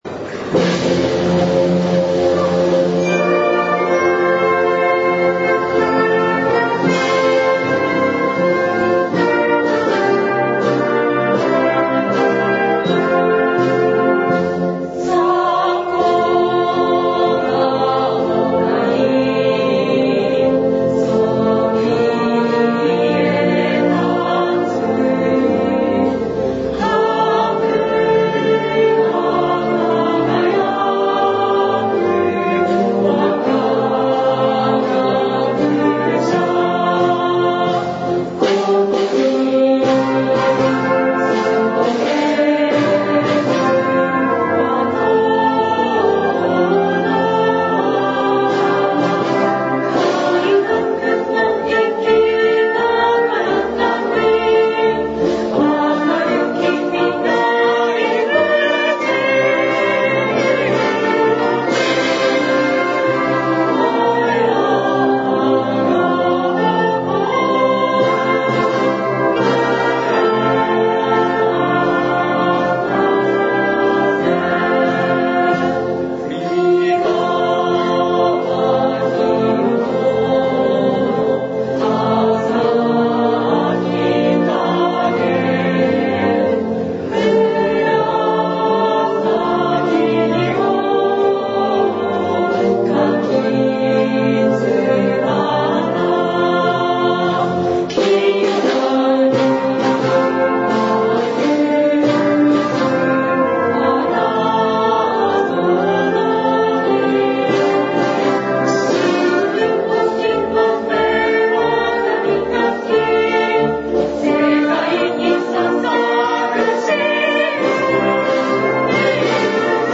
school-song.mp3